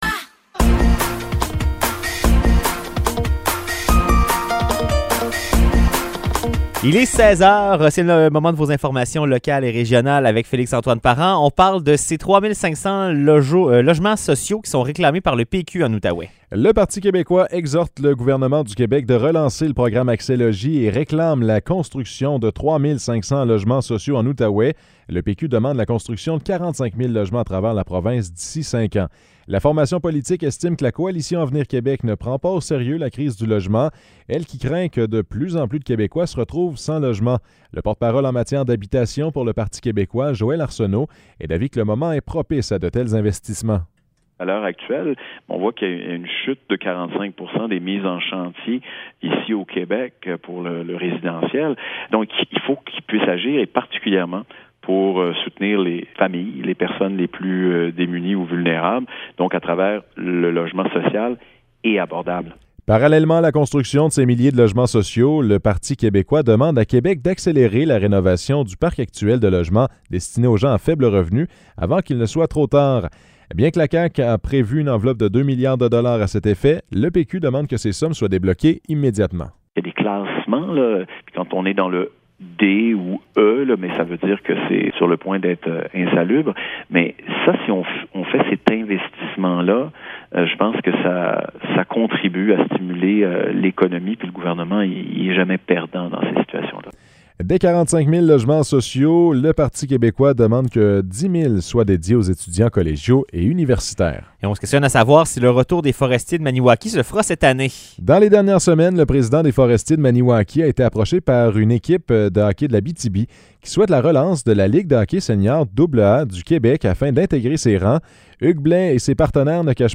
Nouvelles locales - 20 février 2023 - 16 h